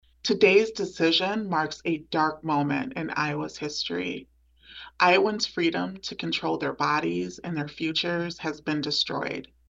She made her comments on a video call after the decision was announced Friday.